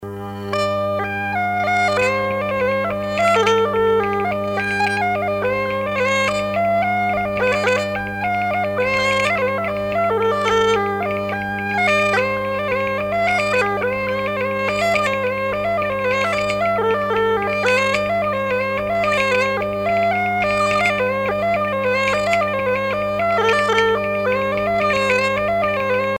danse : bourree
Pièce musicale éditée